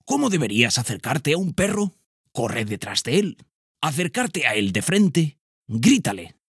TEST PERROS DESCONOCIDOS-Narrador-02.ogg